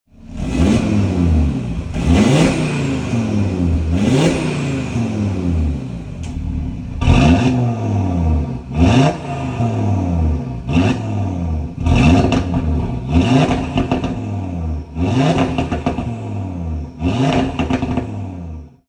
• Aluminium Engine Block Model: (Non-OPF DAZA Engine that is louder than post 2019 models)
Hear its sharp growls and guttural undertone!
• RS Sports Exhaust (£1,000)
audi-rs3-8v-daza-saloon-audi-exclusive-olive-green-czx-sound.mp3